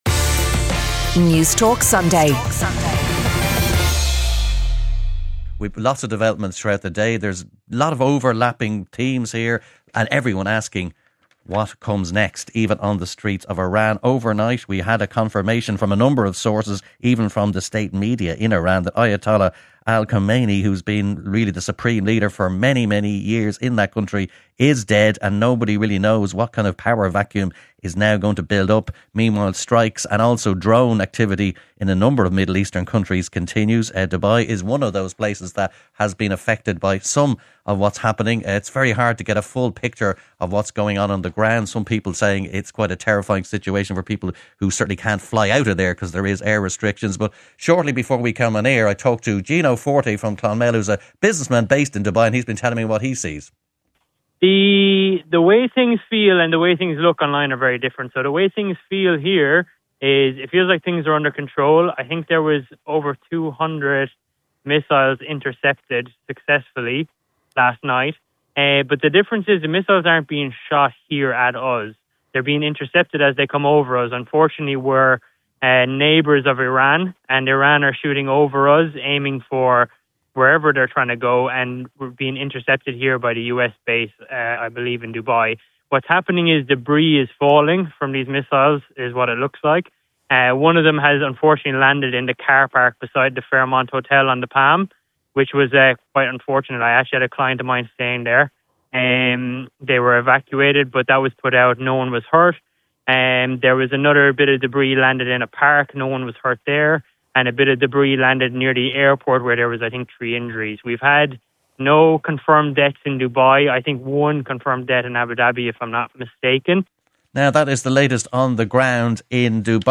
Sunday Newspaper Panel March 1st